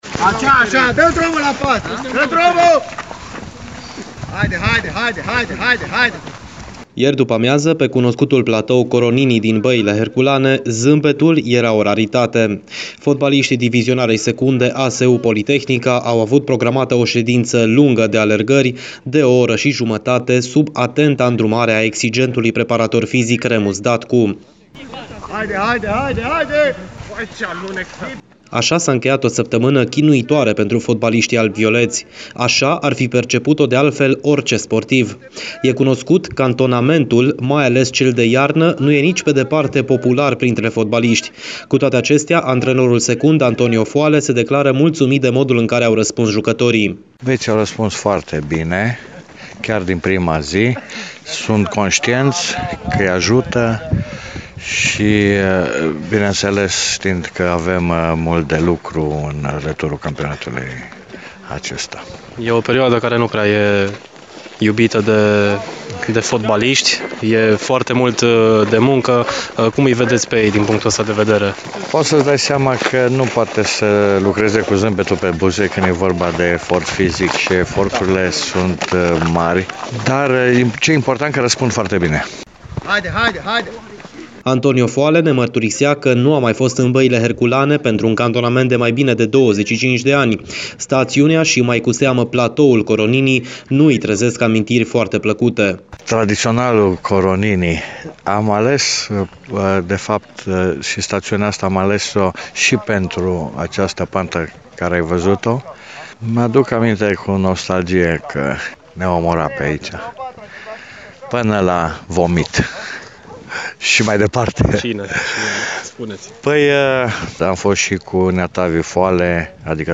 AR-29-ian-Reportaj-cantonament-ASU-la-Herculane.mp3